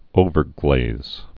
(ōvər-glāz)